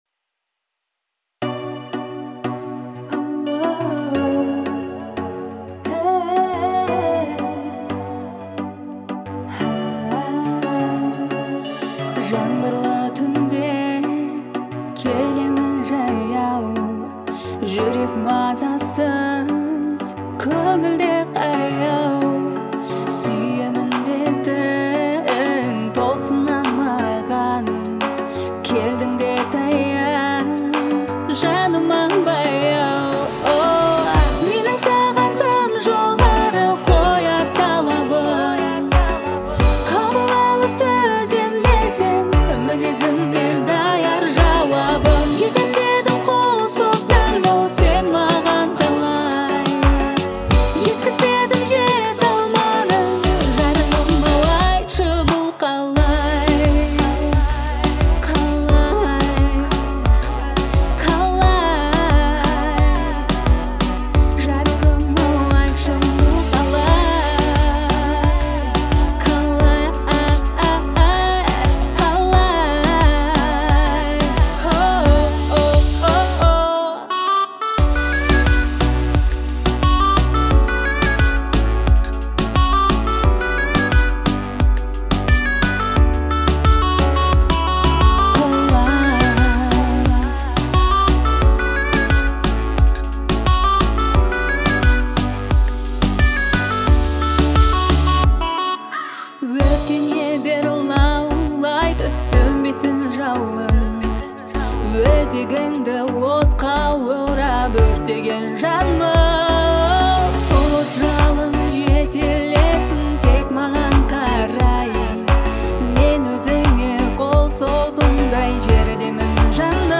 нежная и меланхоличная песня